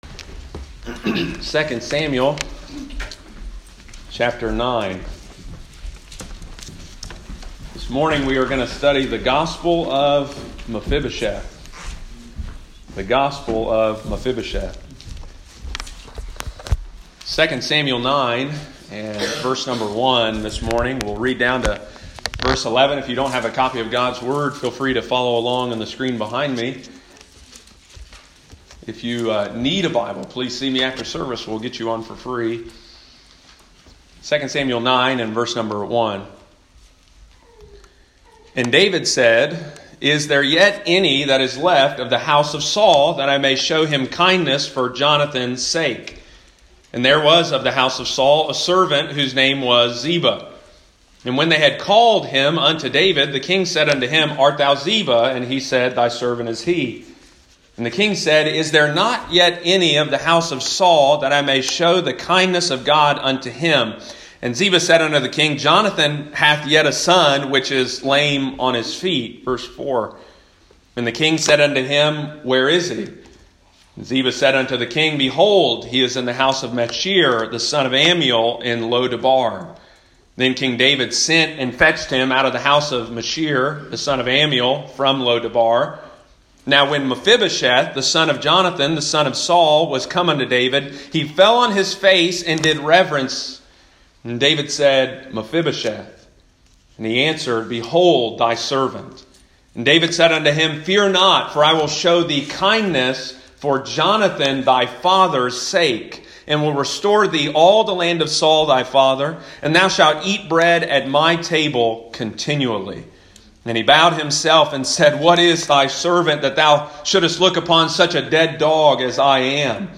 Sunday morning, October 4, 2020.